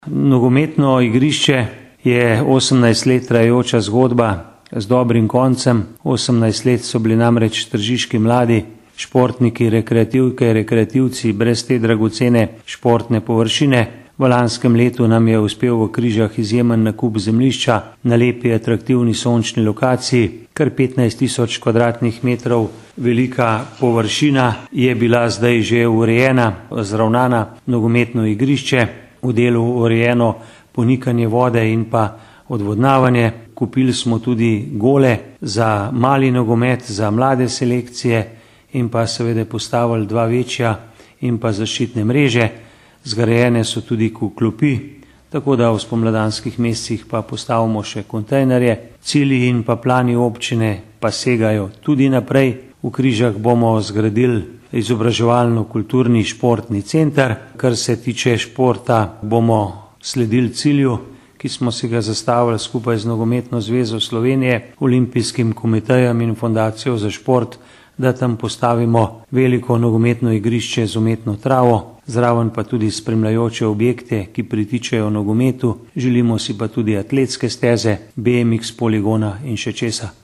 izjava_mag.borutsajoviczupanobcinetrzic_nogometnoigriscekrize.mp3 (1,9MB)